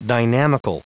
Prononciation du mot dynamical en anglais (fichier audio)
Prononciation du mot : dynamical